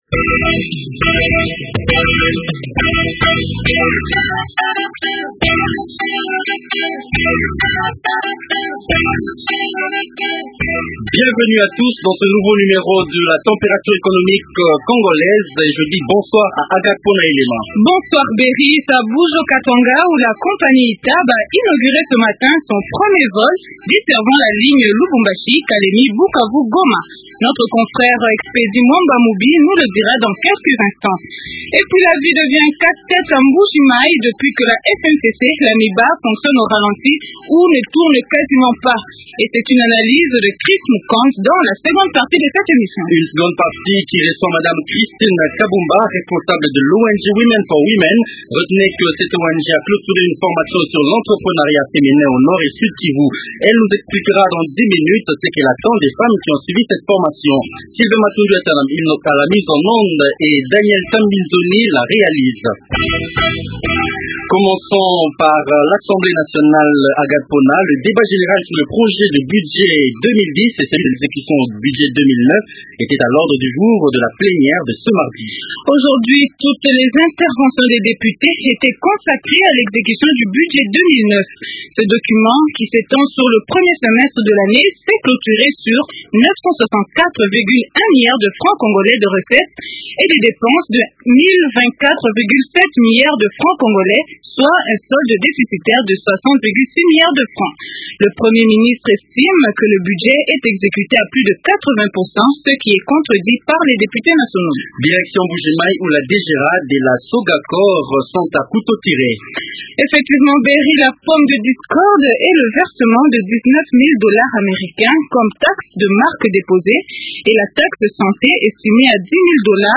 C’est l’écoreportage du jour. Une première à Kalemie, la compagnie ITAB relie désormais Lubumbashi-Kalemie-Bukavu-Goma à la satisfaction des opérateurs économiques.